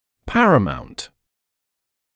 [‘pærəmaunt][‘пэрэмаунт]высший; главный, основной, первостепенный